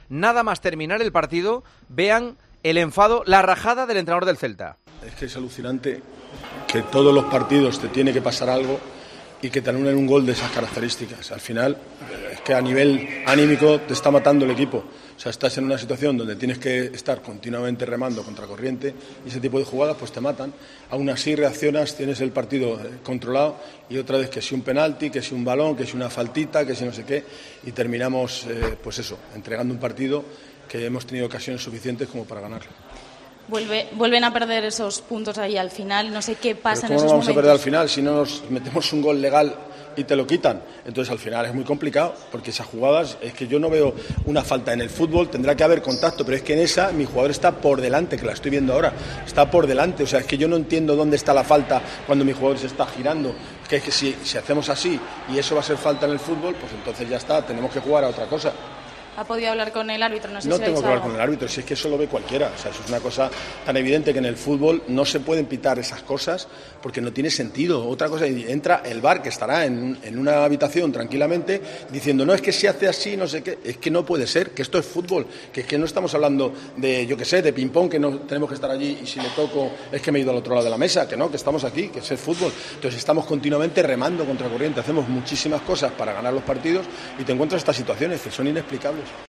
Tremendo enfado de Rafa Benítez en el Estadio de Gran Canaria contra los arbitrajes que recibe el Celta: "¿Cómo no vamos a perder al final si te quitan lo que tenías ganado?"